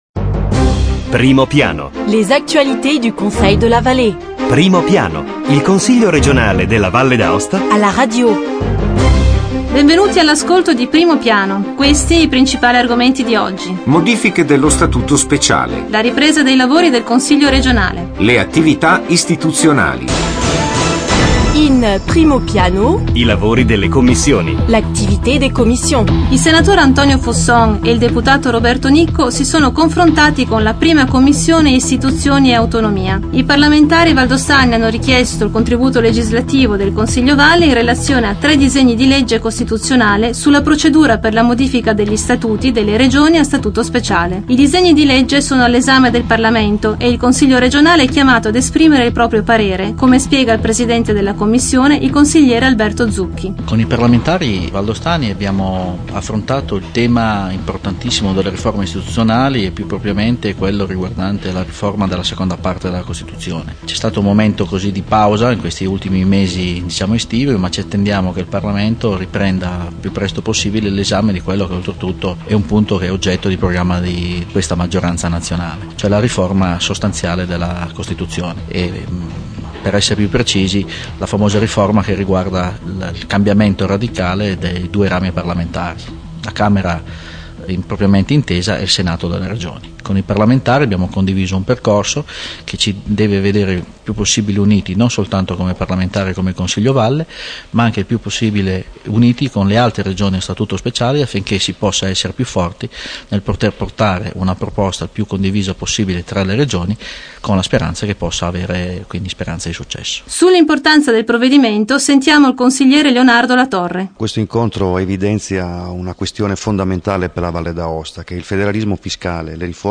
Evénements et anniversaires Documents liés 23 septembre 2009 Primo piano Le Conseil r�gional � la radio: approfondissement hebdomadaire sur l'activit� politique, institutionnelle et culturelle de l'assembl�e l�gislative.
avec les interviews aux Conseillers Alberto Zucchi e Leonardo La Torre